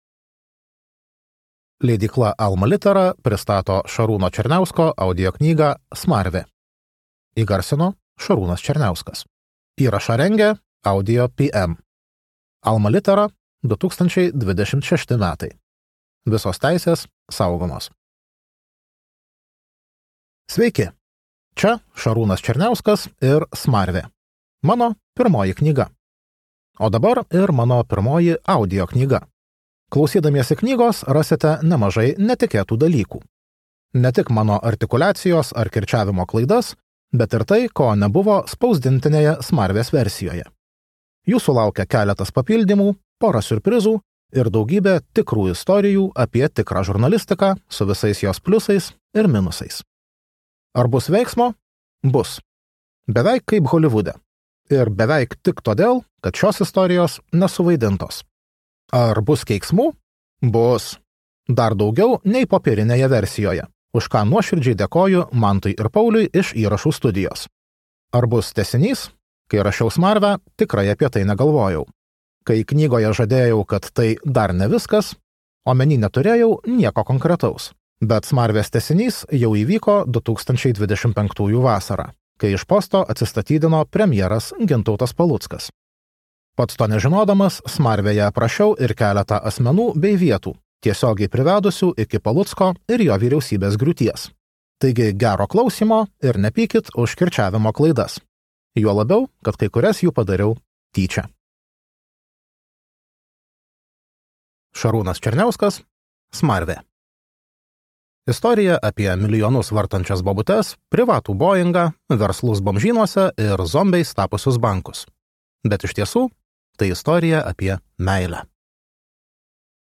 Smarvė | Audioknygos | baltos lankos